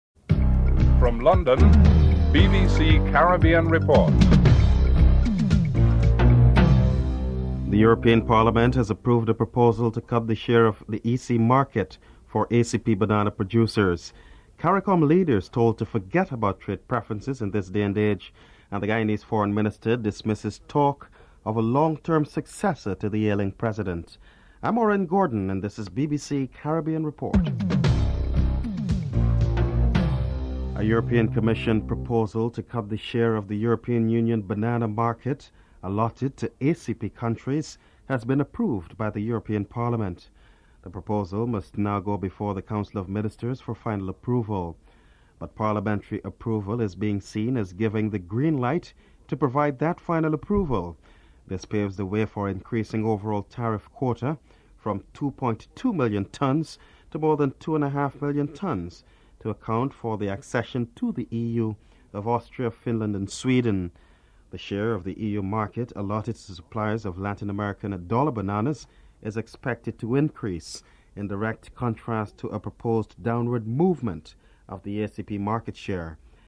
1. Headlines (00:00-00:30)
Clement Rohee, Foreign Minister of Guyana is interviewed (11:52-15:22)